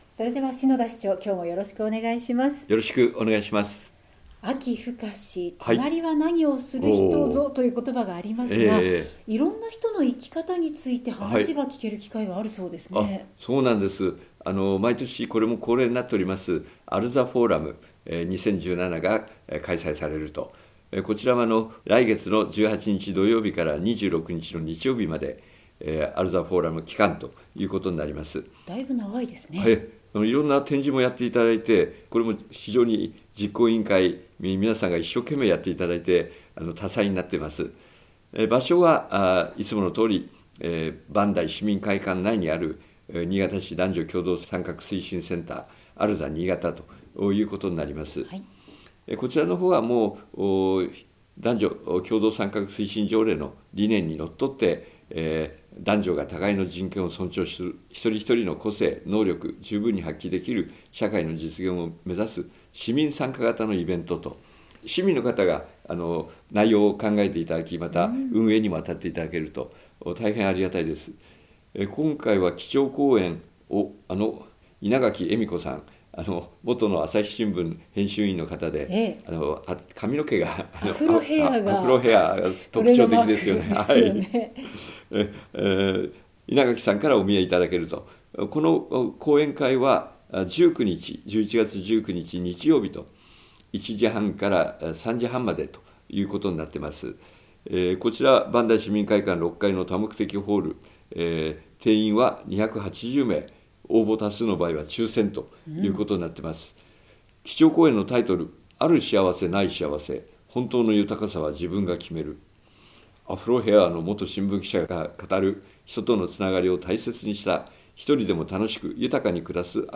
篠田市長の青空トーク | RADIOCHAT76.1MHz | ページ 13